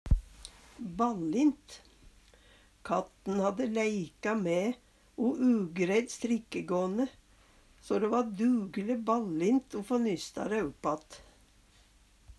DIALEKTORD PÅ NORMERT NORSK ballint vanskeleg, innvikla Eksempel på bruk Katten hadde leika mæ strikkagåne, o dæ va dugle ballint o få nysta dæ uppatt.